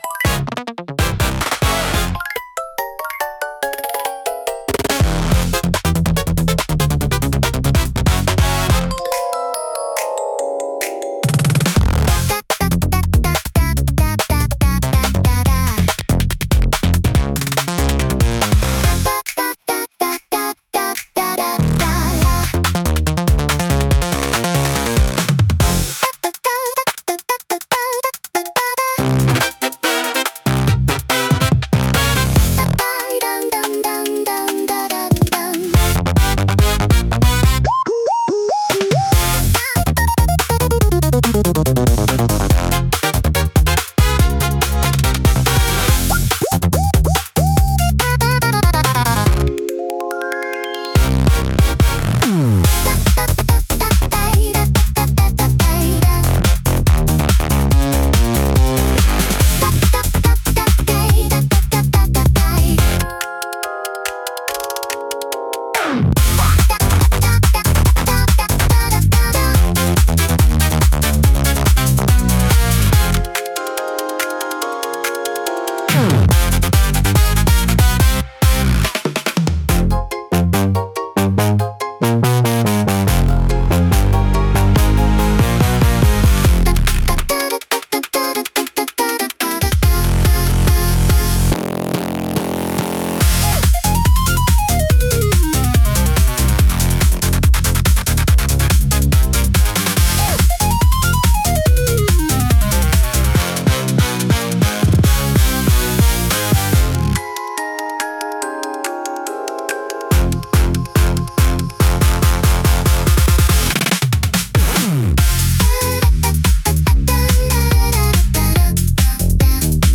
イメージ：インスト,アバンギャルド・ポップ,エレクトロ・ポップ,グリッチポップ,謎
インストゥルメンタル（Instrumental）